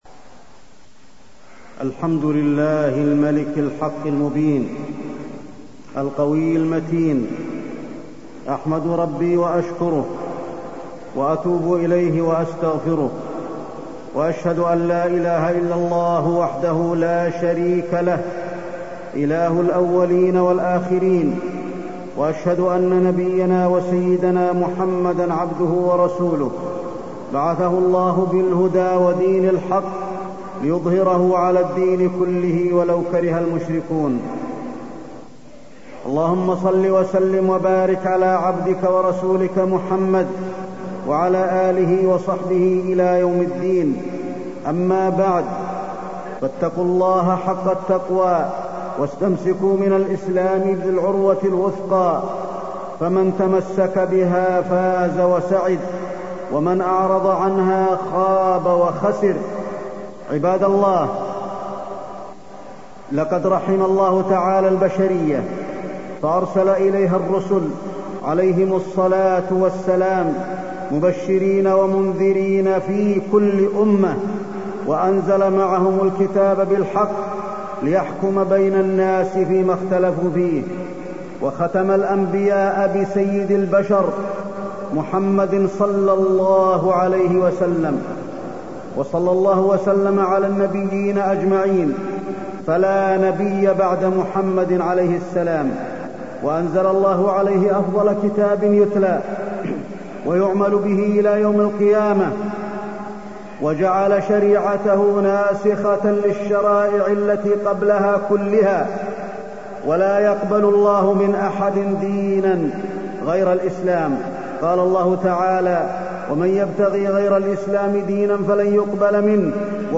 تاريخ النشر ٢٧ رجب ١٤٢٣ هـ المكان: المسجد النبوي الشيخ: فضيلة الشيخ د. علي بن عبدالرحمن الحذيفي فضيلة الشيخ د. علي بن عبدالرحمن الحذيفي الإعلام الغربي ومحاربة الإسلام